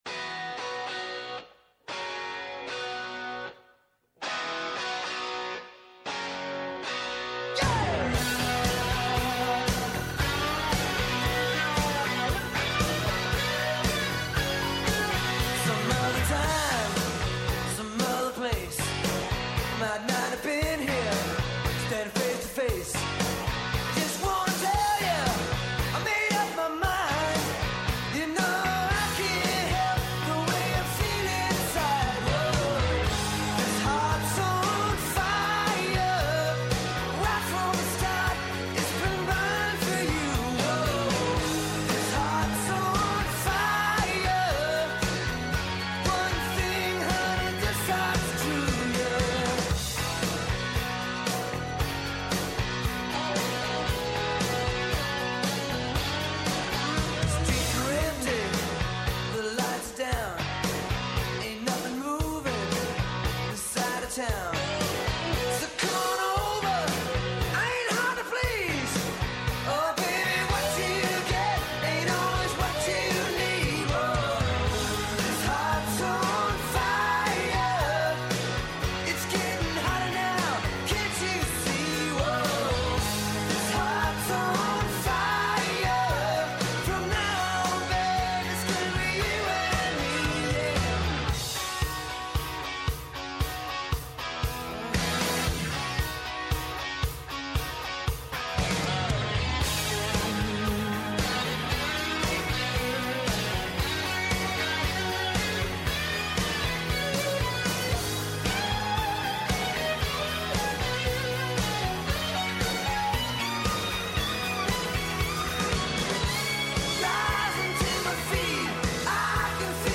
Άνθρωποι της επιστήμης, της ακαδημαϊκής κοινότητας, πολιτικοί, ευρωβουλευτές, εκπρόσωποι Μη Κυβερνητικών Οργανώσεων και της Κοινωνίας των Πολιτών συζητούν για όλα τα τρέχοντα και διηνεκή ζητήματα που απασχολούν τη ζωή όλων μας από την Ελλάδα και την Ευρώπη μέχρι την άκρη του κόσμου.